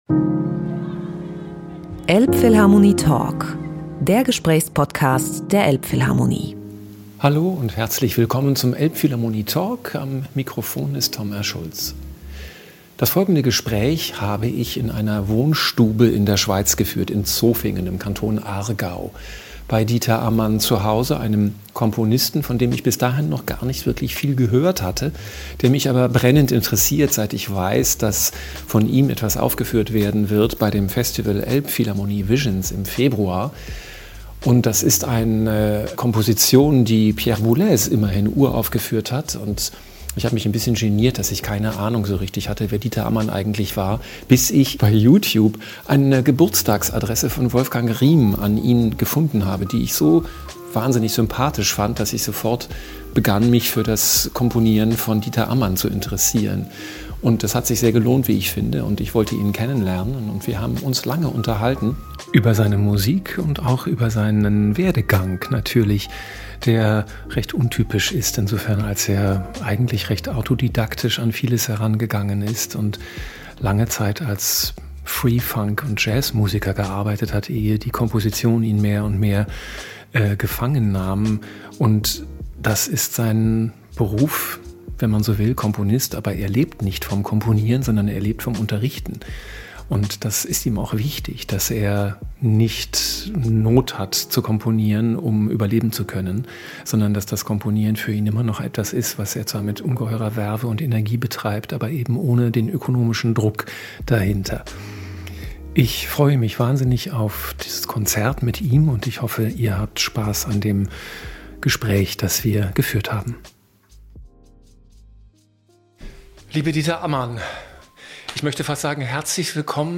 Im Gespräch für den »Elbphilharmonie Talk«, aufgenommen in seinem Wohnzimmer in Zofingen, Kanton Aargau, erzählt Dieter Ammann vom ziemlich freien Musikmachen als Kind und wie das aktive, gestaltende Hören, das er viel besser beherrscht als das Notenlesen, seinen Werdegang geprägt hat und sein Verständnis von Musik bis heute bestimmt. Ammann spricht über die Mühen des Komponierens, seine Neigung zum Motorischen, zum Drive, auch über die Genauigkeit, ohne die Expressivität in der Kunst nicht zu haben ist. Man erfährt im Gespräch mit ihm viel über den künstlerischen Prozess, über Freundschaft, über das Wesen der Musik.